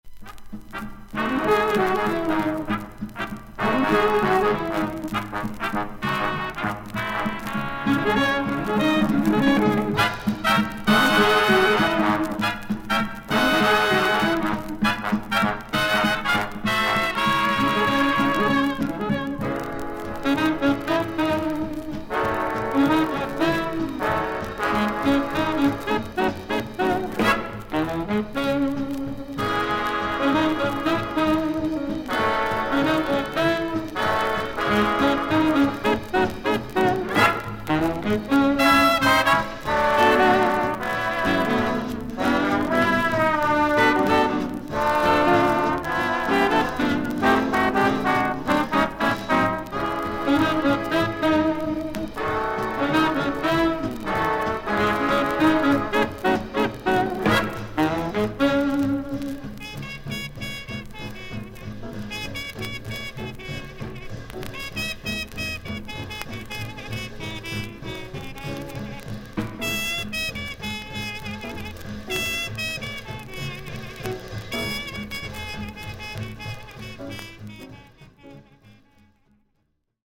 少々軽いパチノイズの箇所あり。少々サーフィス・ノイズあり。クリアな音です。
ジャズ・ピアニスト。